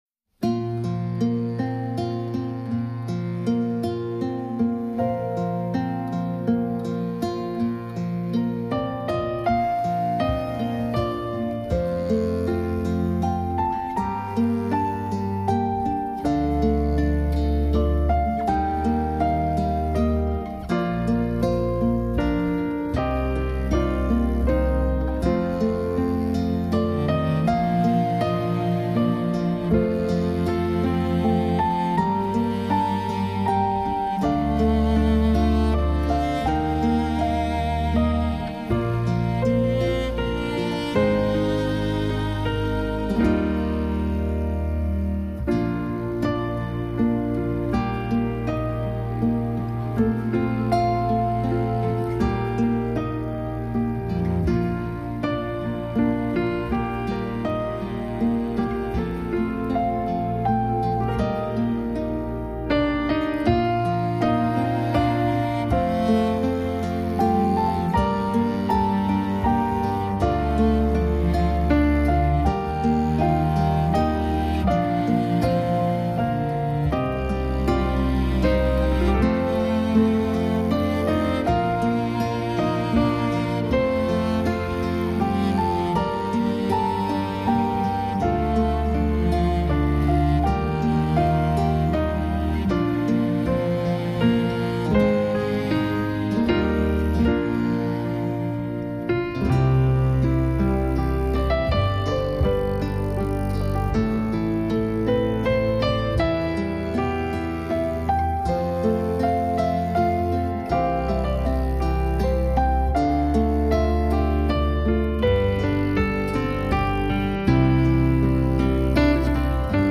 Genres: New Age/General